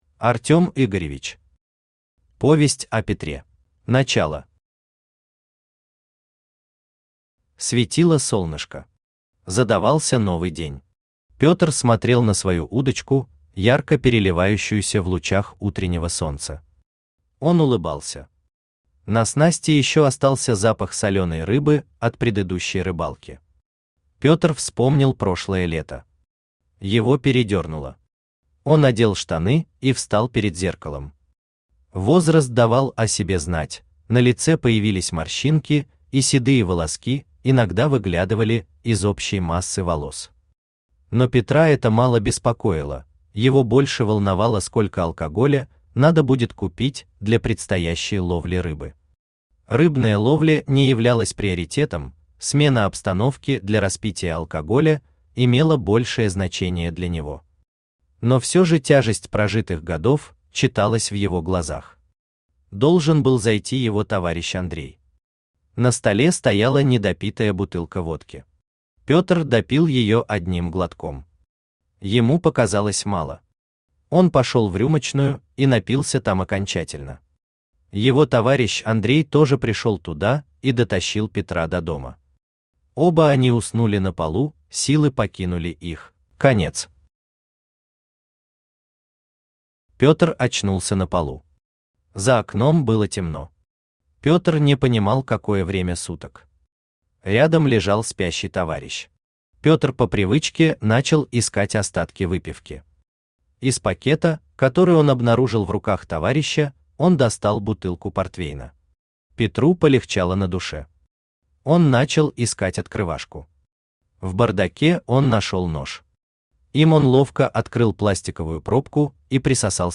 Aудиокнига Повесть о Петре Автор Артём Игоревич Читает аудиокнигу Авточтец ЛитРес.